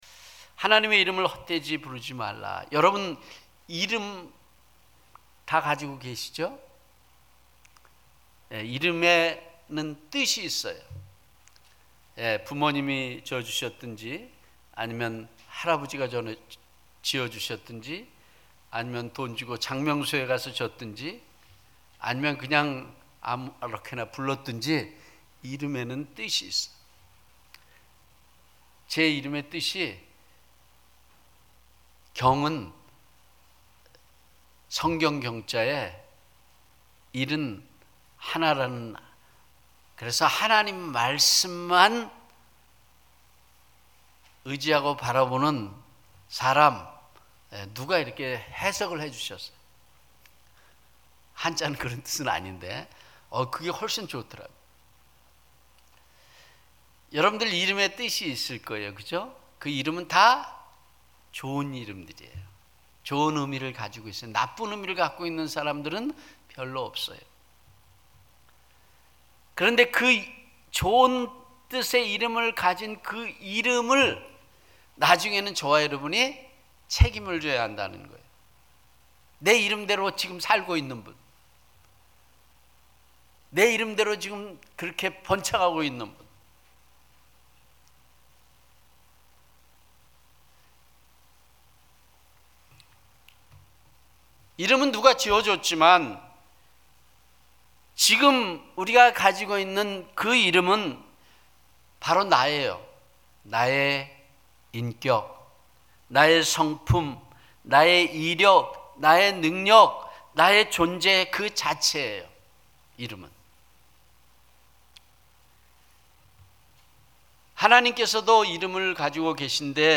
1 주일설교